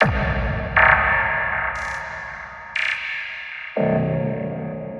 Processed Hits 10.wav